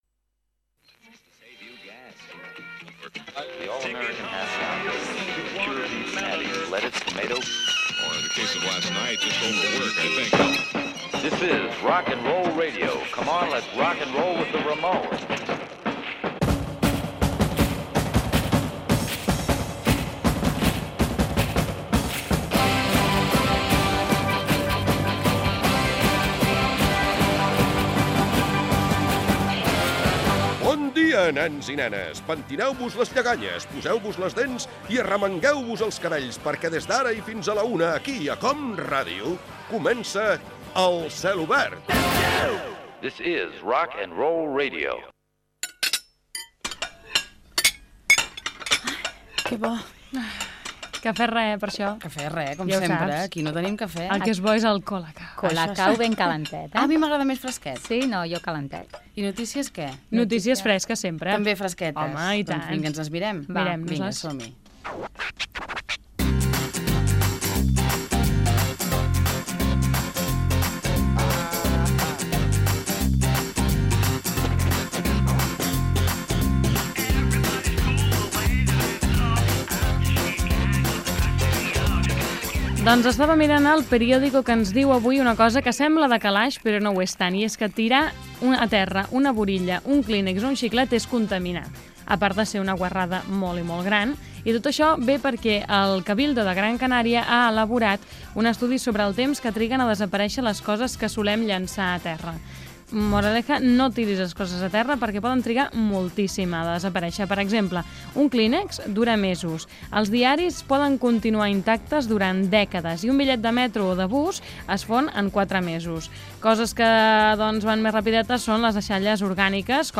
d57d8e943ff4c29659a575b8f8a58d68183be24c.mp3 Títol COM Ràdio Emissora COM Ràdio Barcelona Cadena COM Ràdio Titularitat Pública nacional Nom programa El celobert (COM Ràdio) Descripció Inici del programa. Comentaris sobre notícies curioses publicades per la premsa del dia Gènere radiofònic Entreteniment